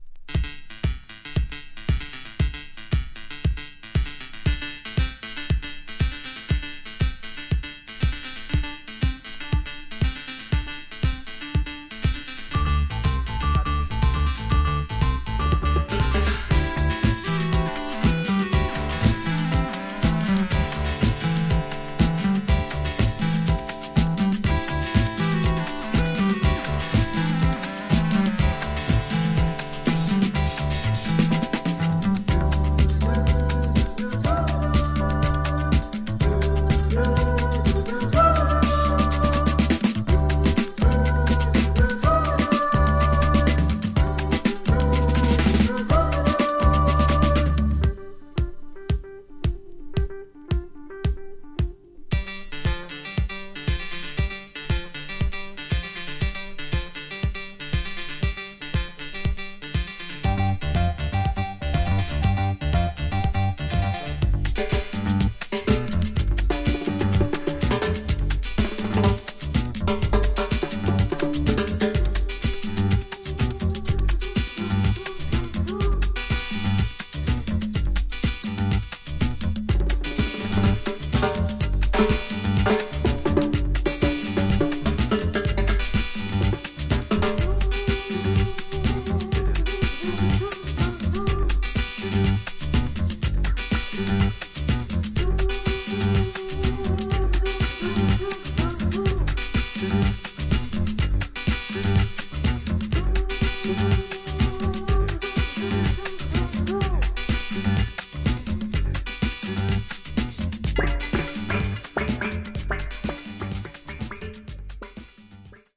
Tags: Cuba , Latin , Fusion